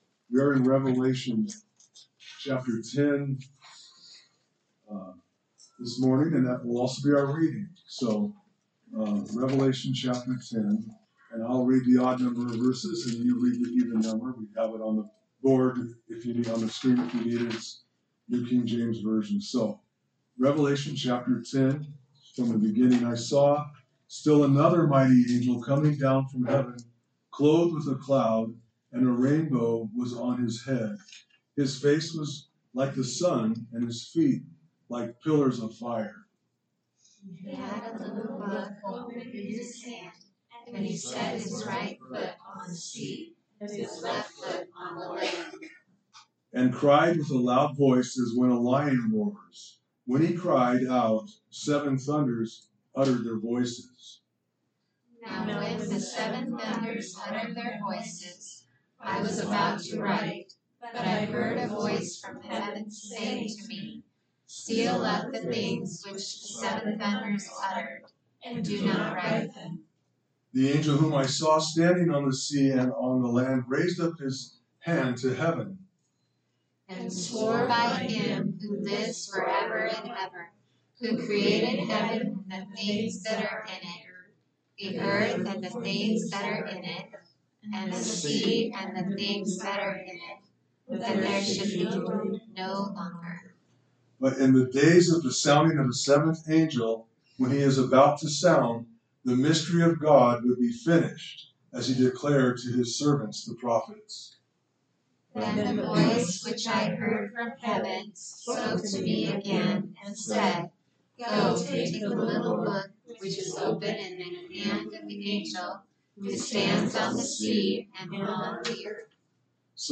A message from the series "Revelation."